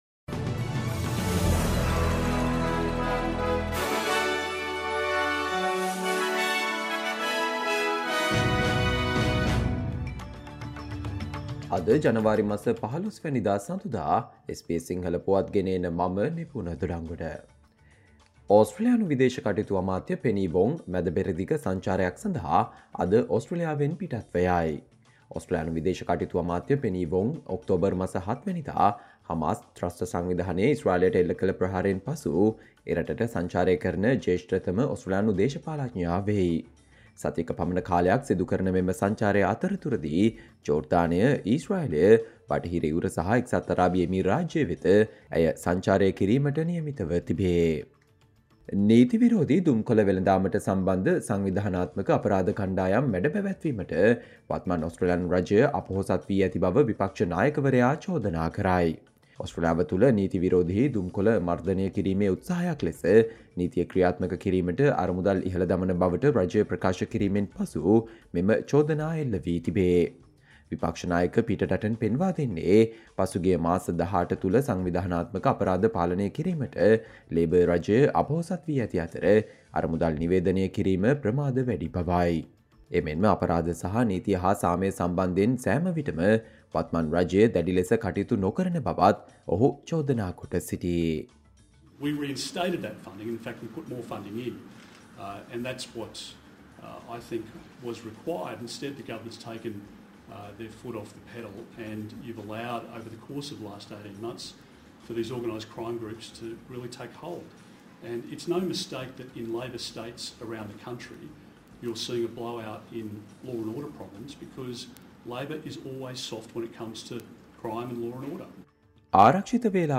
Australia news in Sinhala, foreign and sports news in brief - listen, Monday 15 January 2024 SBS Sinhala Radio News Flash